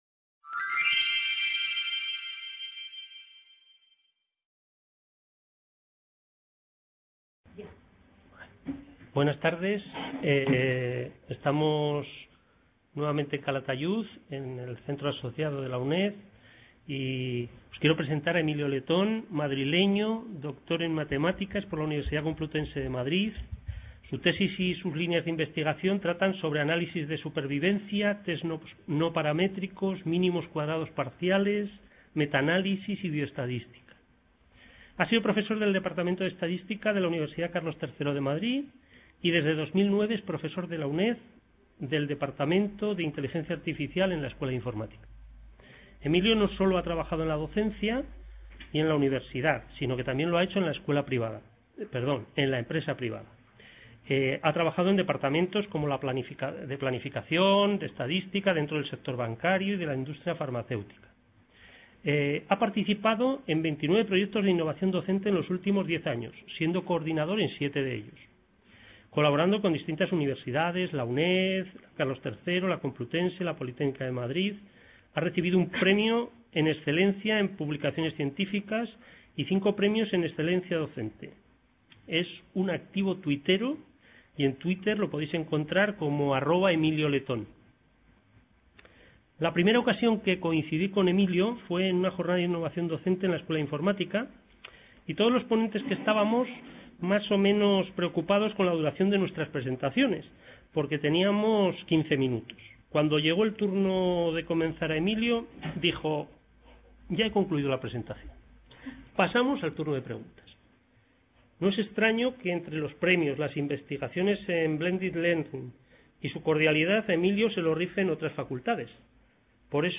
Seminario: Minilibros electrónicos modulares (MEM).